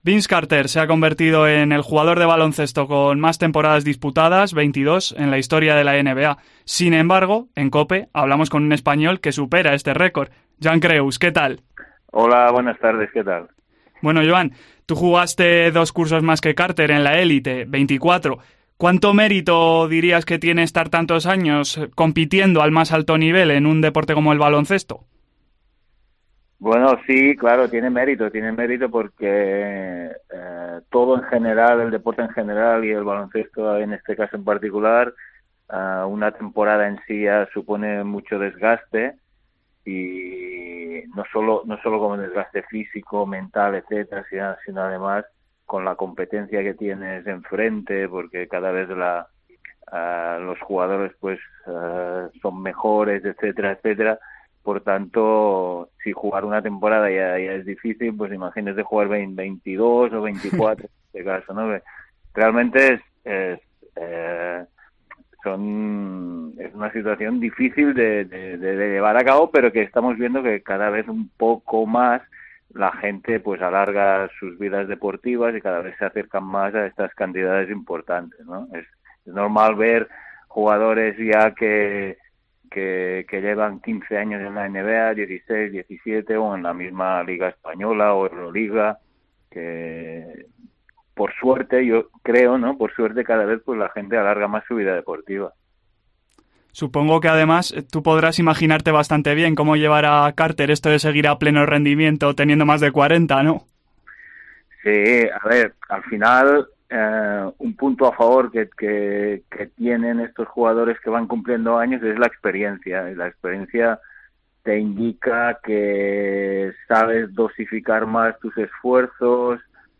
COPE entrevista al líder del mejor Manresa de siempre, que supera el tope histórico de permanencia en la NBA al haber competido 24 temporadas en la...